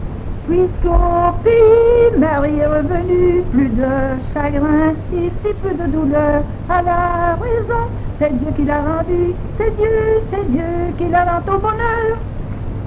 Genre : chant
Type : chanson de saut à la corde
Lieu d'enregistrement : Jolimont
Support : bande magnétique
Incipit : G G C C E E E E G G